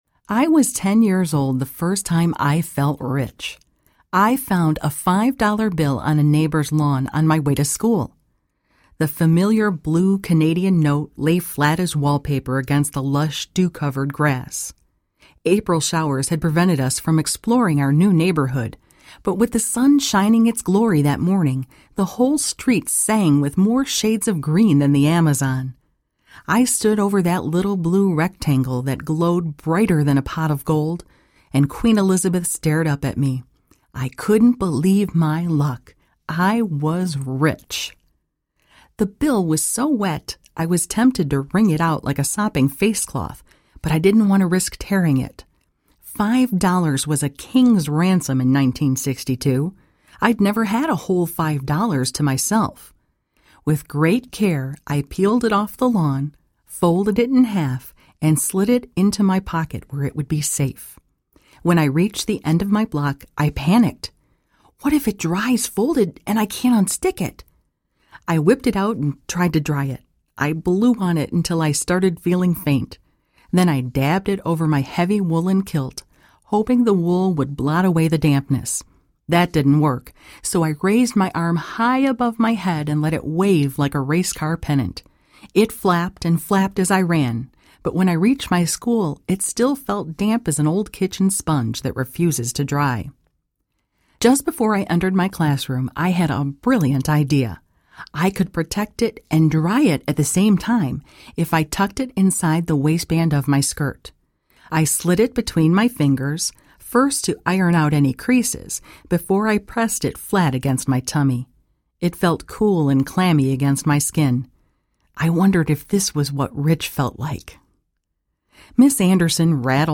Narrator
5.0 Hrs. – Unabridged